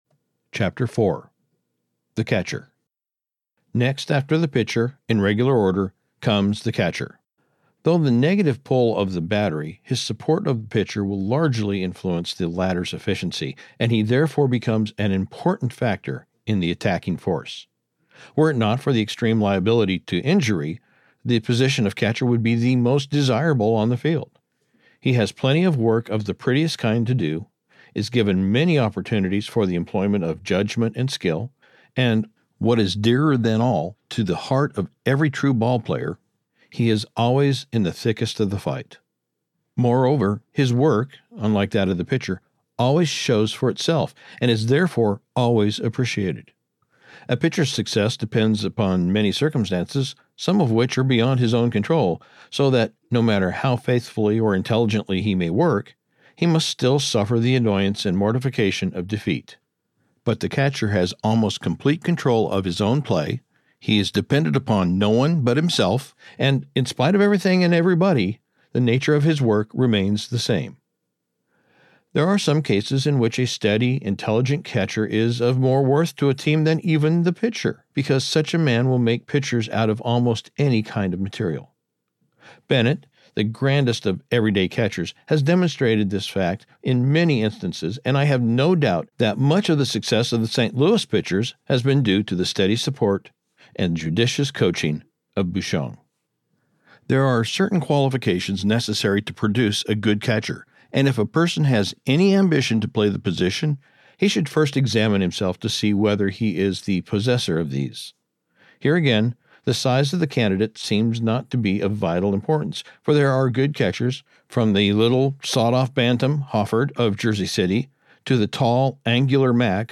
Audiobook production Copyright 2025, by GreatLand Media